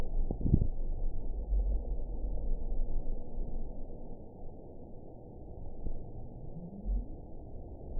event 920763 date 04/07/24 time 23:40:19 GMT (1 year, 2 months ago) score 8.08 location TSS-AB09 detected by nrw target species NRW annotations +NRW Spectrogram: Frequency (kHz) vs. Time (s) audio not available .wav